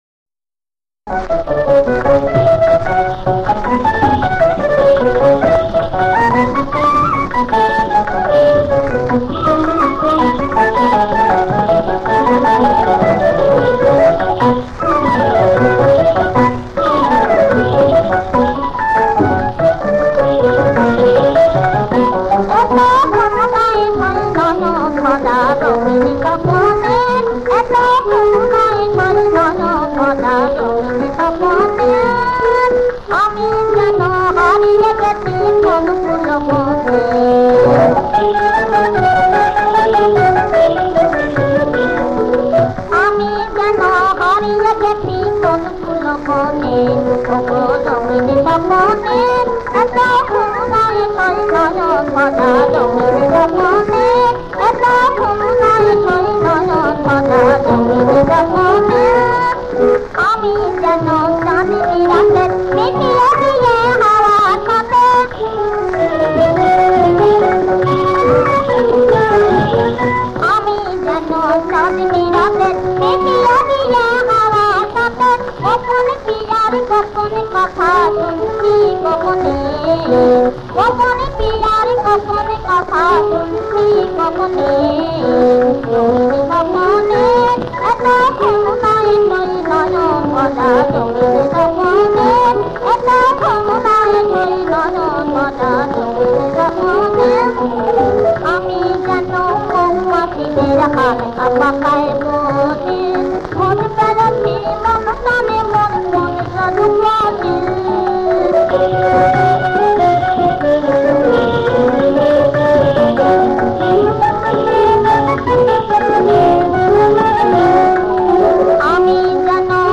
তাল: দ্রুত-দাদ্‌রা
• সুরাঙ্গ: স্বকীয় বৈশিষ্ট্যের গান
• তাল: তাল দ্রুত দাদরা
• গ্রহস্বর: র্সা